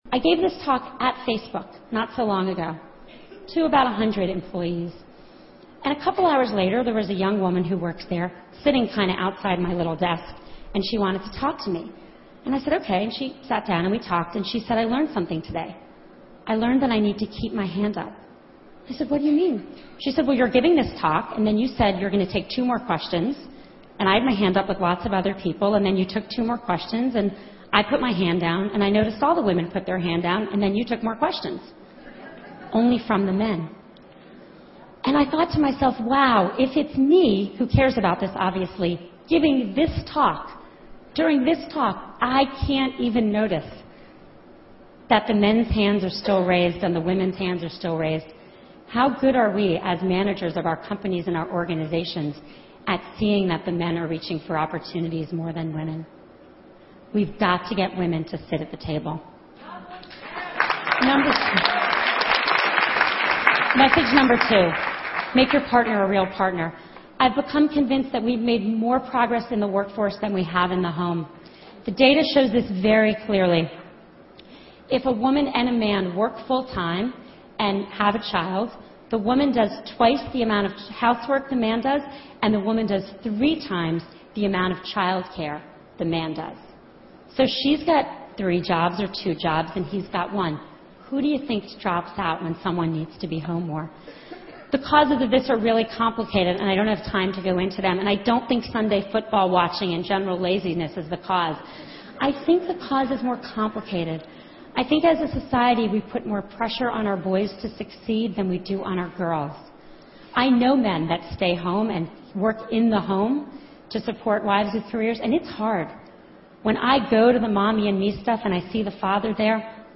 TED演讲:为什么女性领导那么少?(4) 听力文件下载—在线英语听力室